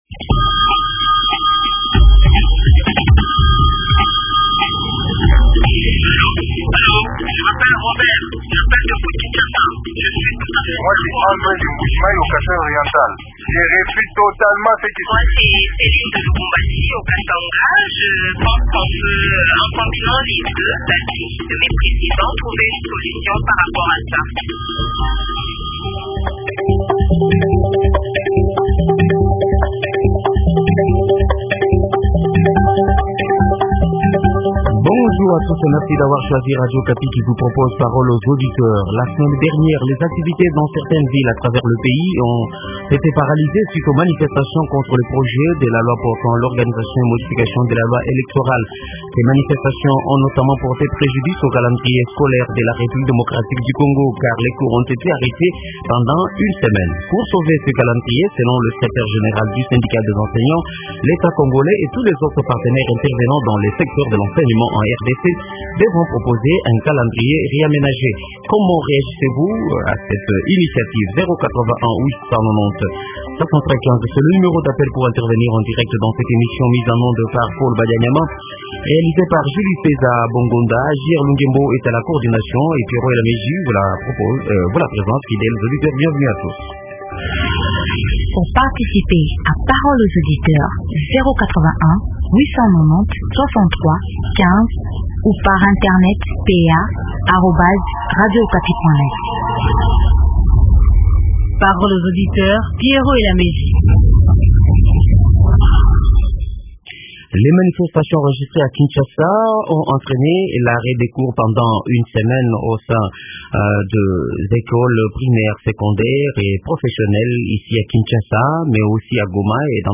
A gauche, le ministre de l’Enseignement primaire et secondaire, Maker Mwangu au cours d’une emission Paroles aux auditeurs à Radio Okapi-Kinshasa.